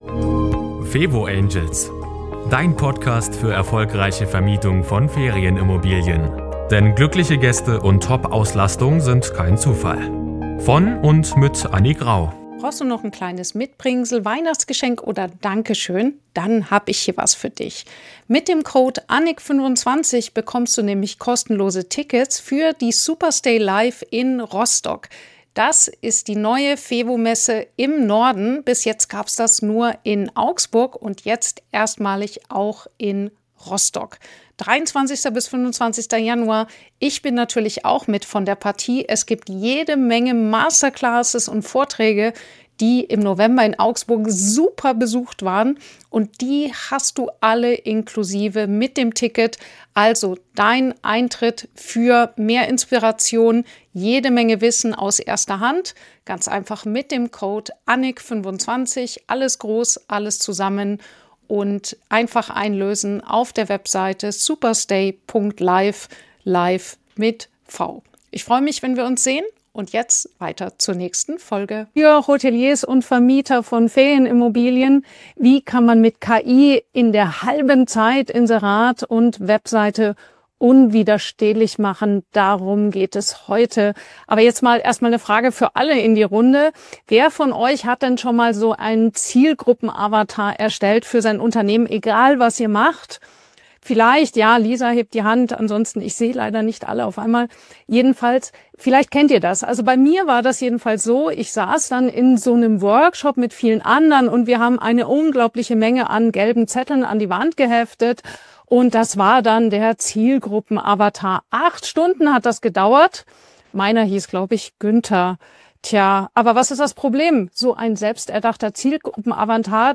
Diese Folge ist ein Livemitschnitt eines Kurzvortrags.